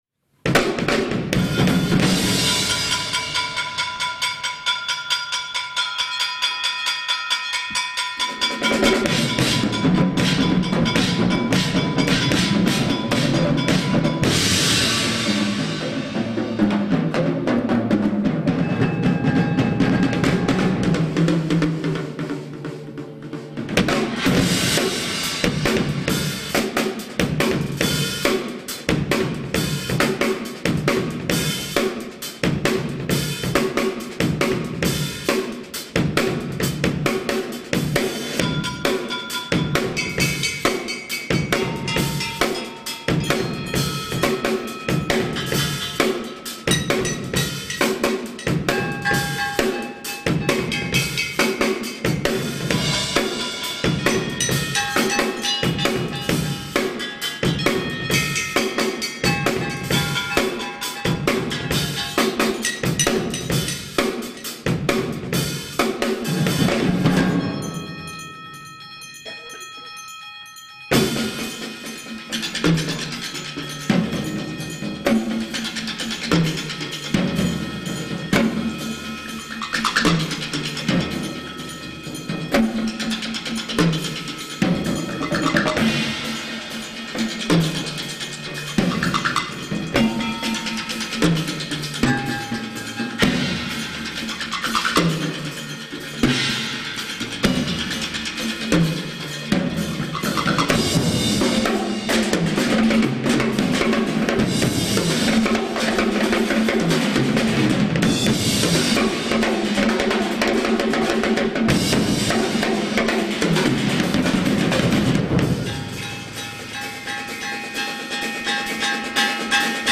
Voicing: Percussion Nonet